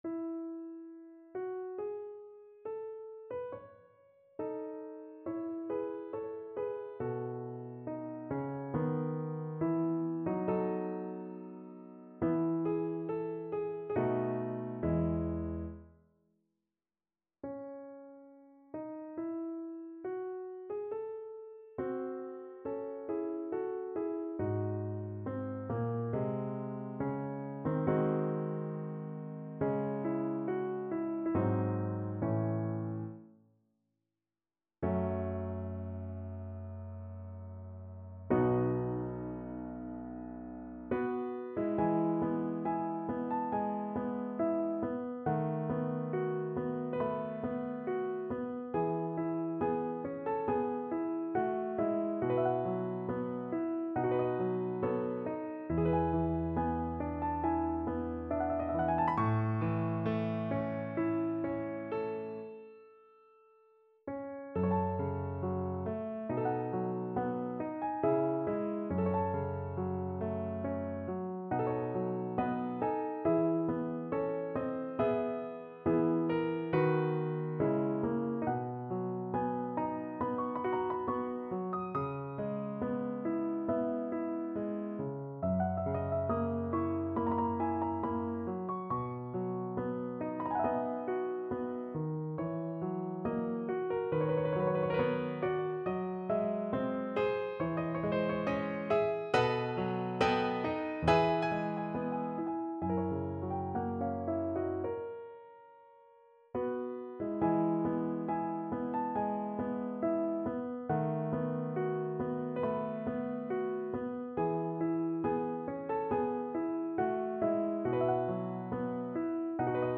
Free Sheet music for Piano
No parts available for this pieces as it is for solo piano.
E major (Sounding Pitch) (View more E major Music for Piano )
4/4 (View more 4/4 Music)
Larghetto (=80) =69
Piano  (View more Advanced Piano Music)
Classical (View more Classical Piano Music)